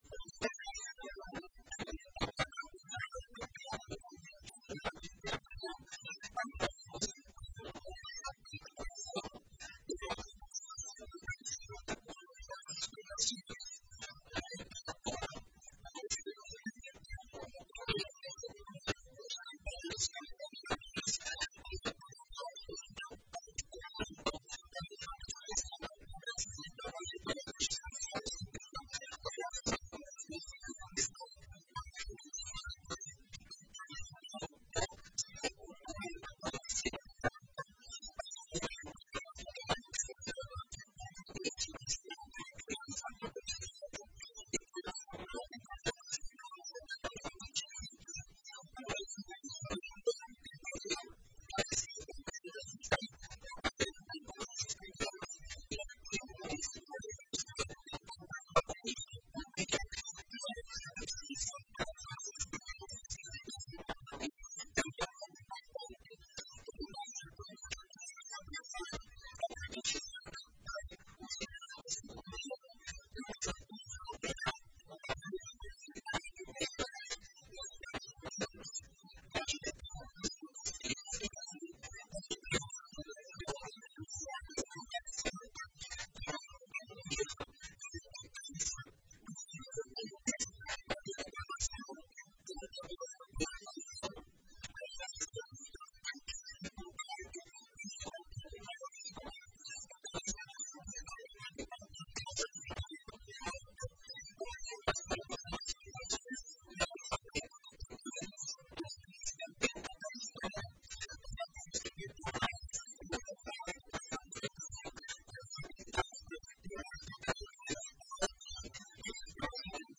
Ouça abaixo um trecho da entrevista concedida pelo titular da Educação de Ijuí, detalhando o lançamento do projeto: